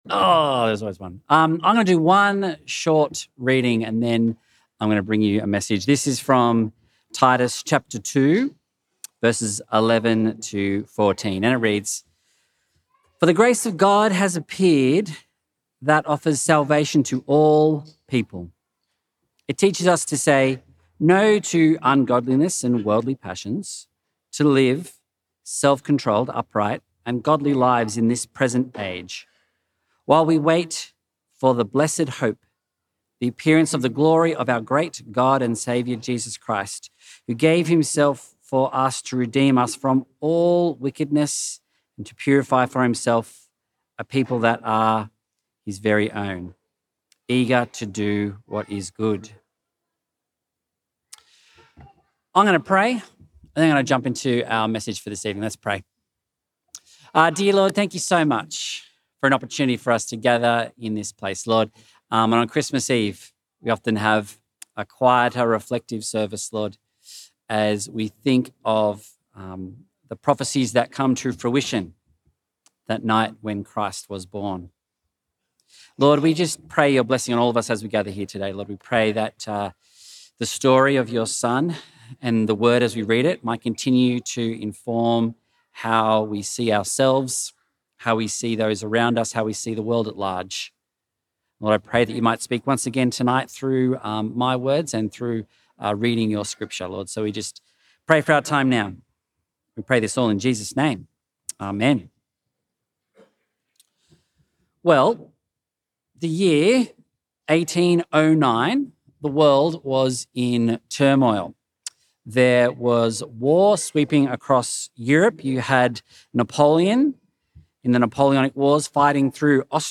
Christmas Eve service from Pittwater Uniting Church.